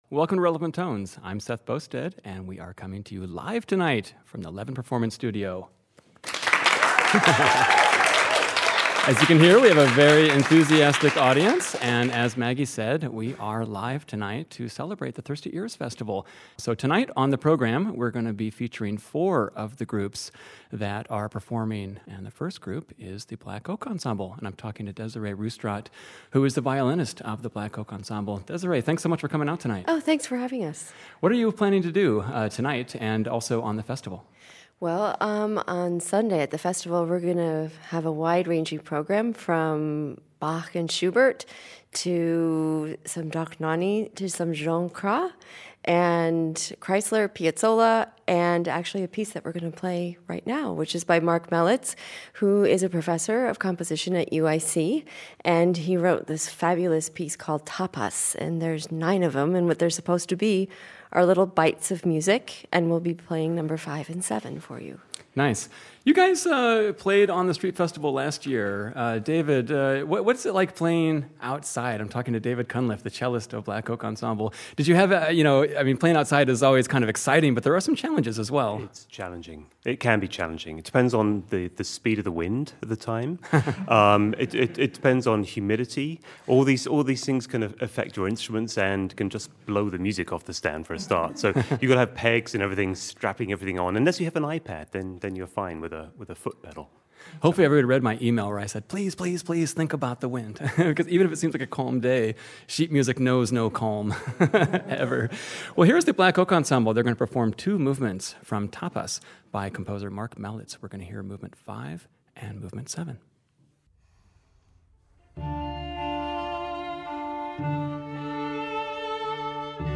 This LIVE broadcast is coming straight our broadcast studio on Friday August 11th featuring several of the exciting ensembles and soloists taking the stage at the fest.